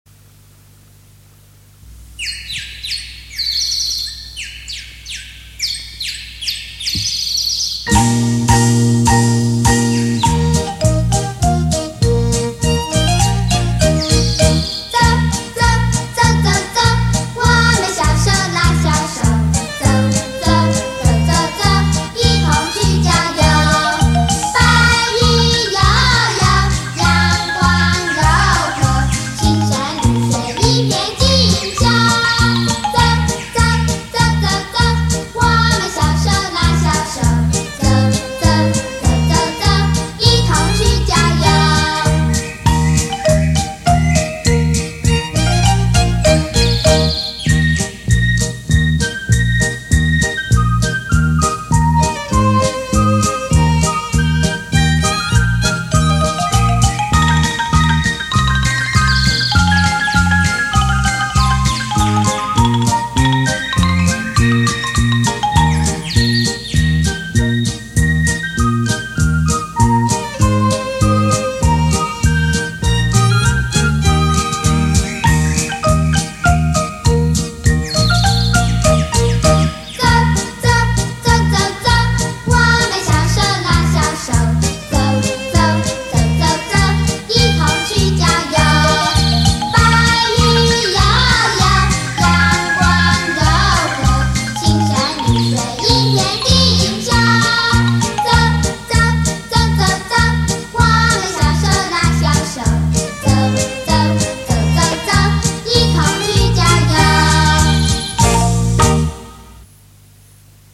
[1/24/2008]送好朋友们——郊游（童谣）